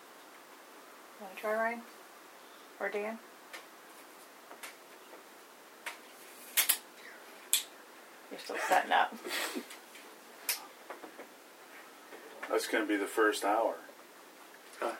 Equipment use: SLS Camera, digital recorders, Ovilus, EMF meters
Audio Captured during Paranormal Investigation
Whisper 2
Colon-WHISPER.wav